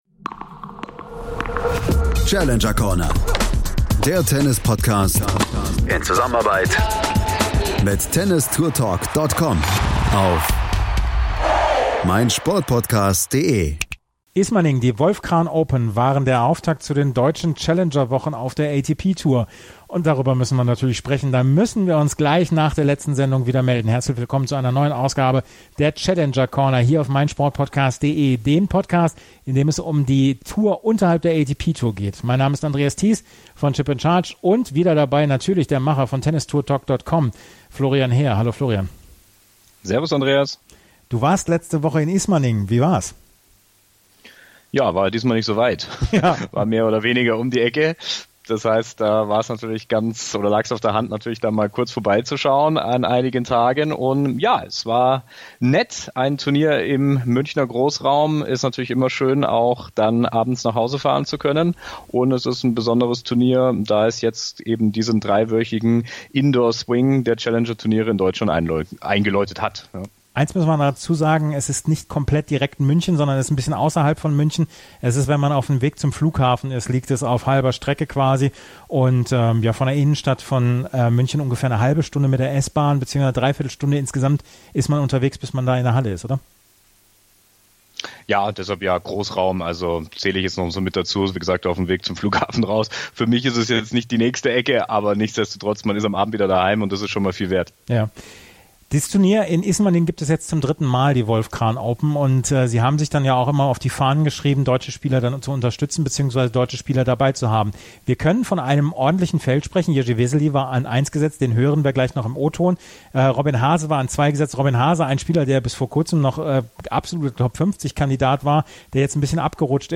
Auch der topgesetzte in diesem Turnier, Jiry Vesely, gab in einem Interview Auskunft über Form und Ziele.
Den Abschluss des Podcasts bildet noch ein Interview mit den Doppelsiegern Quentin Halys und Tristan Lamasine.